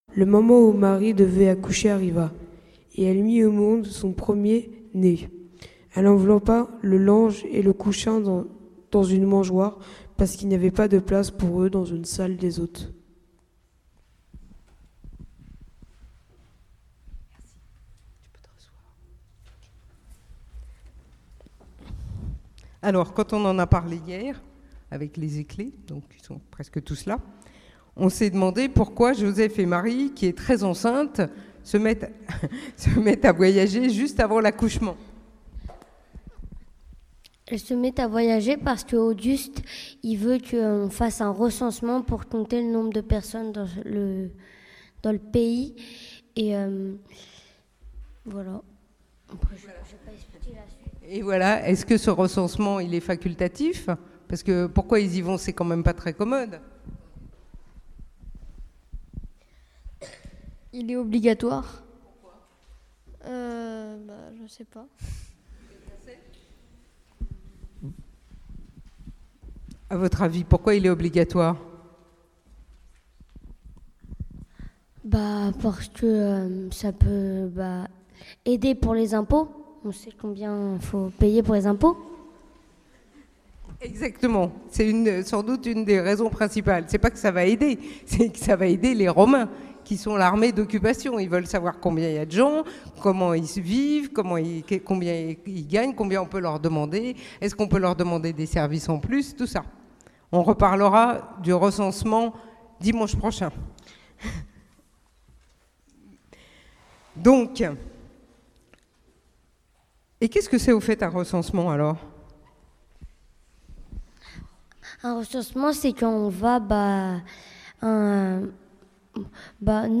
Prédication : La foi et les dogmes : et vous, qui dites-vous que je suis ?